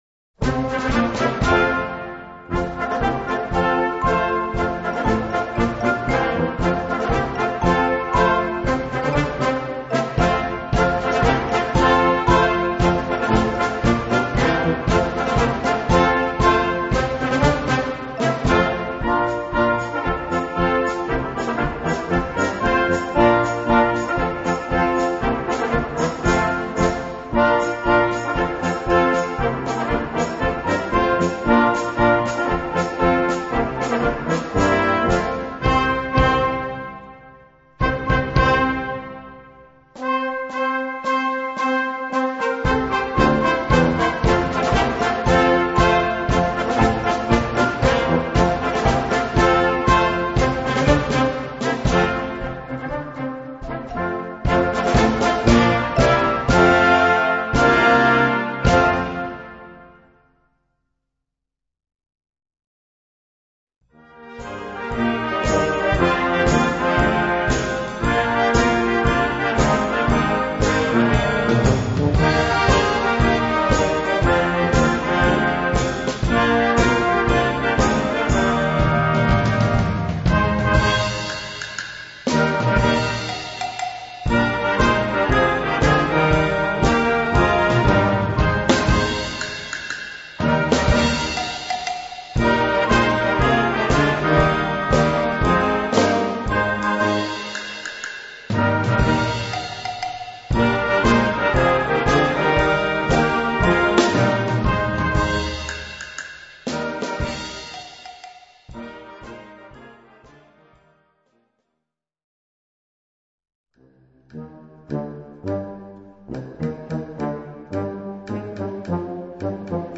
Categorie Harmonie/Fanfare/Brass-orkest